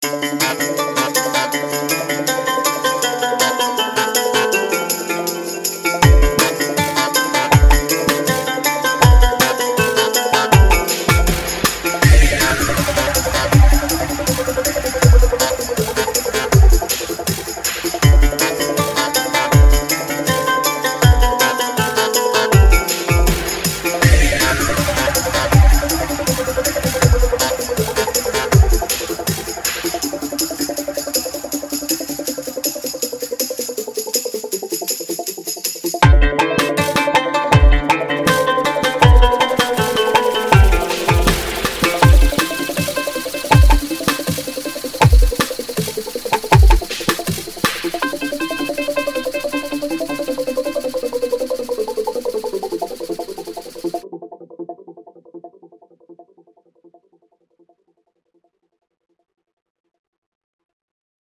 Produção sonora vinheteira, com notas distintas e com ênfase na redundância cíclica.